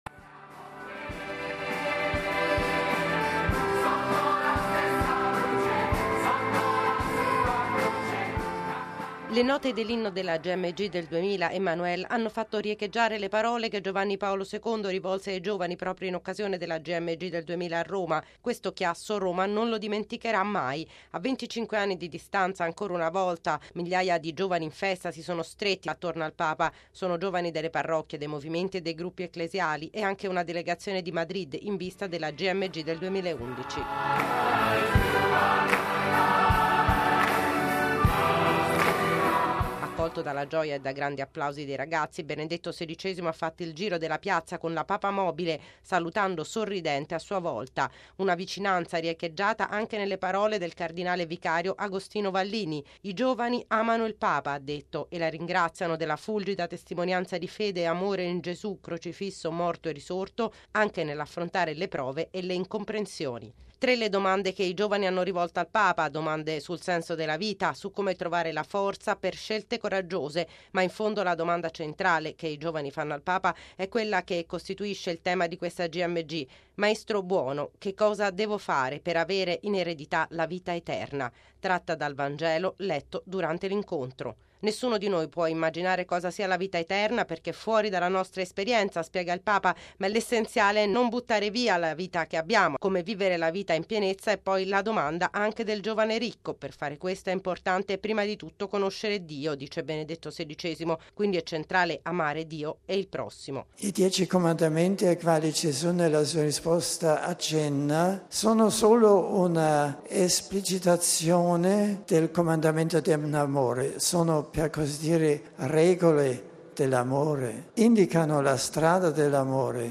E’ stata una grande festa con canti, testimonianze e l’ingresso in piazza della Croce delle Gmg e dell'icona di Maria Salus Populi Romani, accolta dalle fiaccole dei giovani.
(canto)
Accolto dalla gioia e da grandi applausi dei ragazzi, Benedetto XVI ha fatto il giro della piazza con la papa-mobile, salutando sorridente a sua volta.